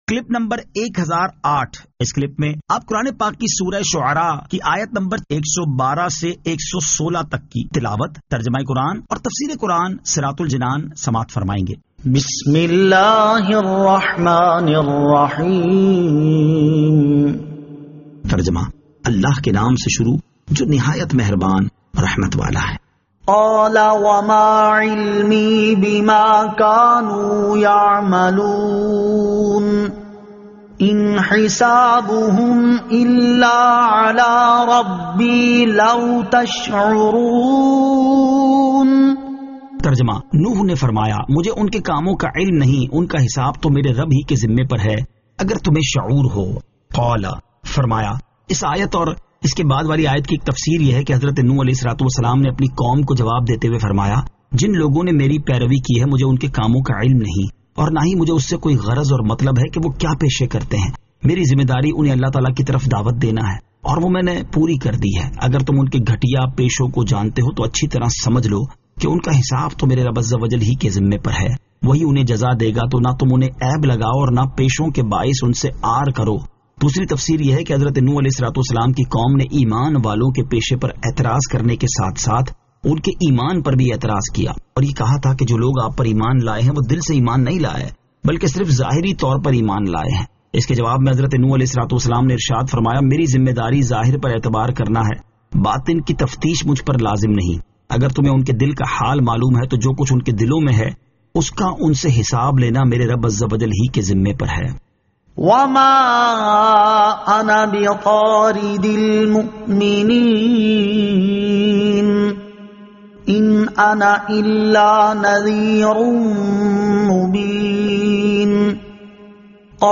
Surah Ash-Shu'ara 112 To 116 Tilawat , Tarjama , Tafseer